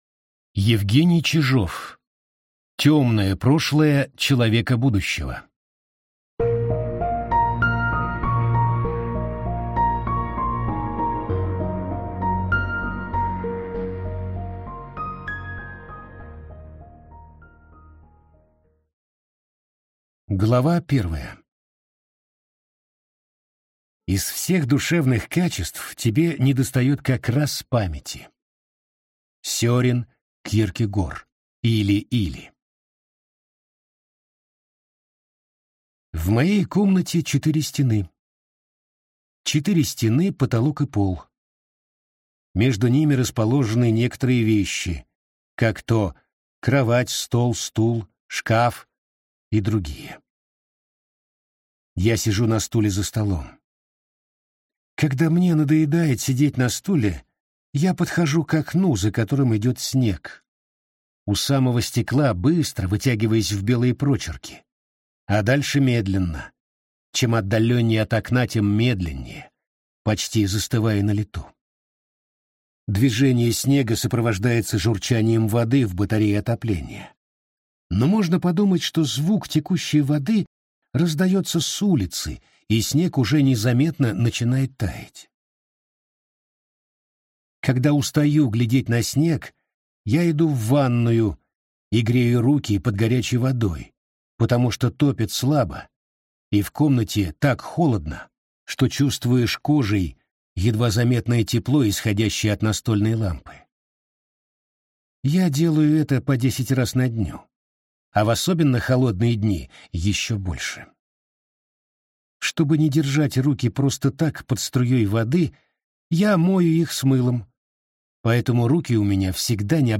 Аудиокнига Темное прошлое человека будущего | Библиотека аудиокниг